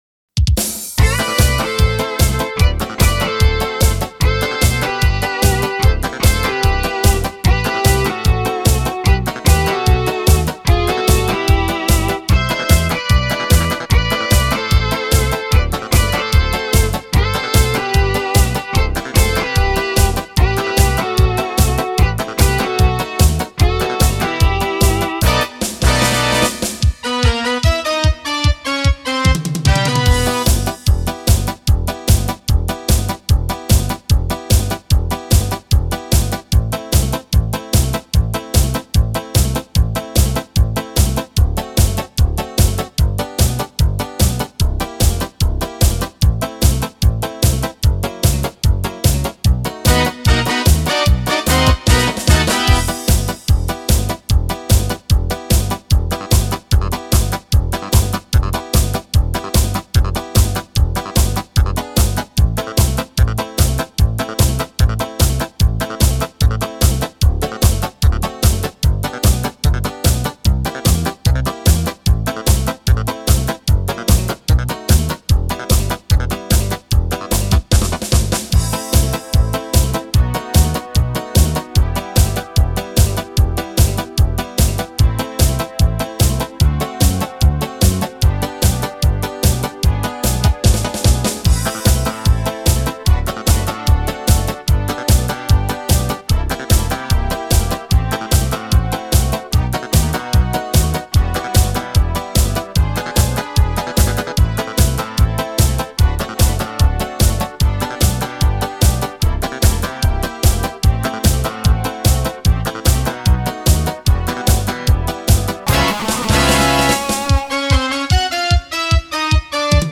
Оно взято с минусовки, которую я и прилагаю